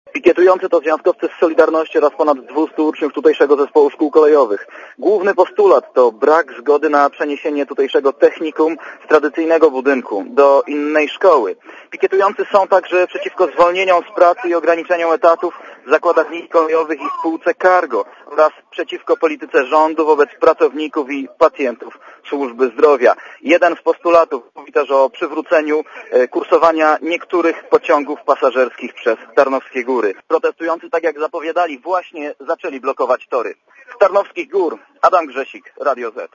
(PAP) Trąbki, petardy syreny i płonące opony w Tarnowskich Górach. Kolejarska Solidarność na Śląsku pikietuje przed tarnogórskim dworcem.
Źródło: PAP Relacja reportera Radia ZET Oceń jakość naszego artykułu: Twoja opinia pozwala nam tworzyć lepsze treści.